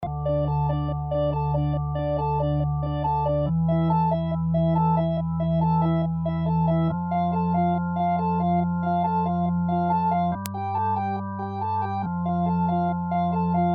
描述：器官环路
Tag: 140 bpm Hip Hop Loops Organ Loops 2.31 MB wav Key : Unknown